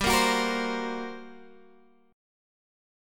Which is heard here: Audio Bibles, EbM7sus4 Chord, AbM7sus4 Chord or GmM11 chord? GmM11 chord